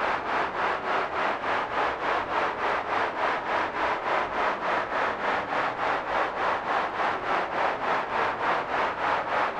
STK_MovingNoiseC-100_01.wav